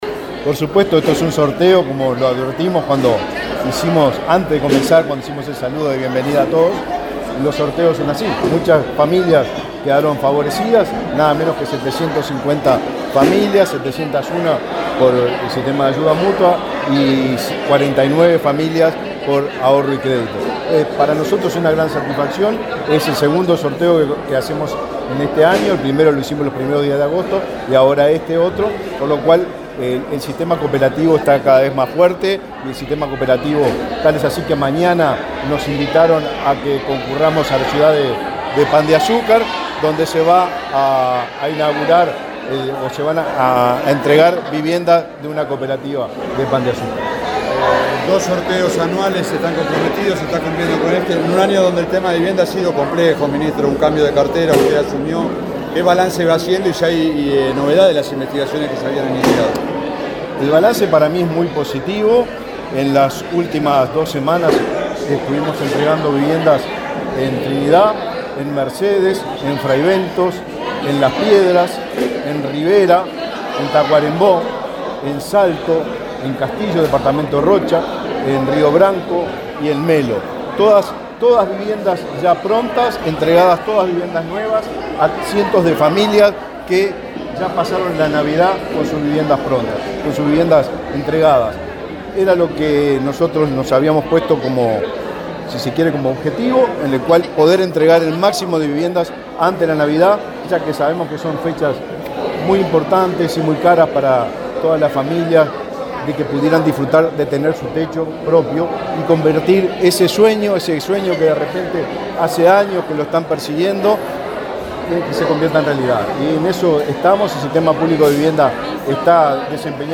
Declaraciones a la prensa del ministro de Vivienda, Raúl Lozano